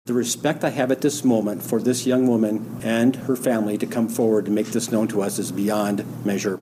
Larimer County Sheriff John Feyen (FYE-en)…
231107-Sheriff-J-Feyen-respect-for-victim-coming-forwardDownload